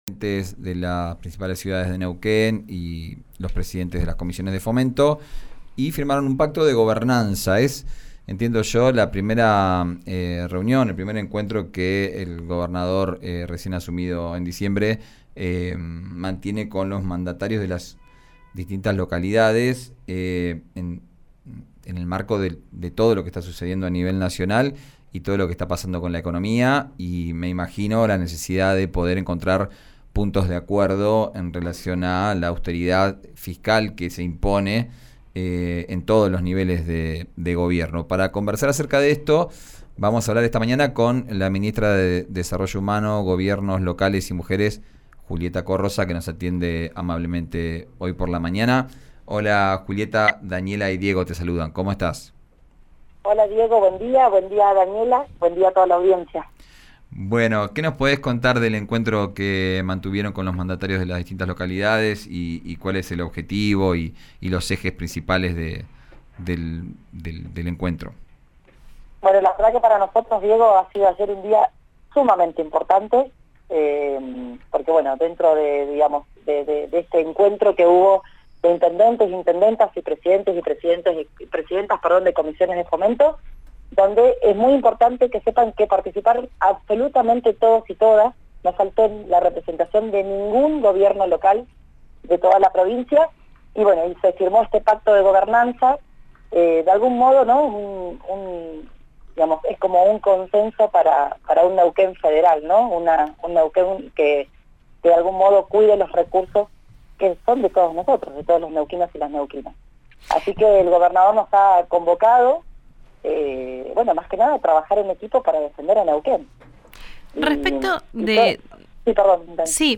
La ministra de Desarrollo Humano, Gobiernos Locales y Mujeres, Julieta Corroza, sostuvo que el consenso y el trabajo en equipo serán fundamental para 'defender a la provincia' ante la falta de recursos de Nación. Escuchá la entrevista con RÍO NEGRO RADIO.